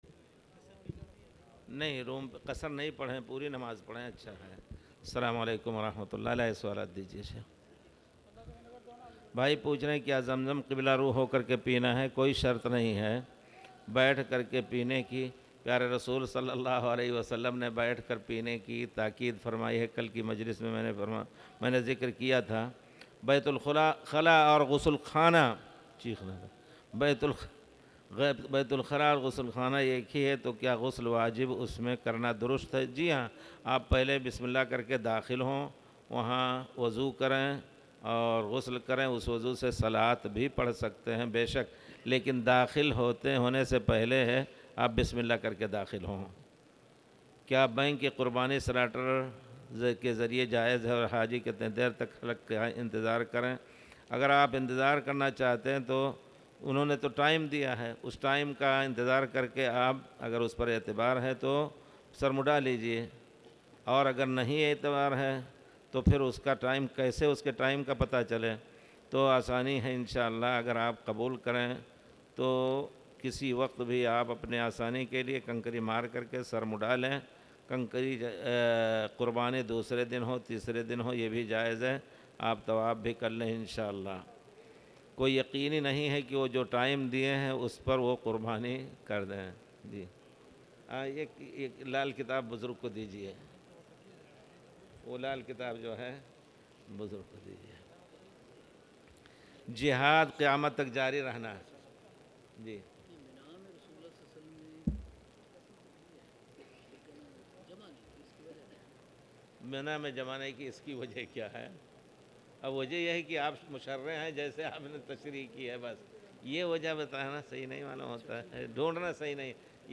تاريخ النشر ٤ ذو الحجة ١٤٣٨ هـ المكان: المسجد الحرام الشيخ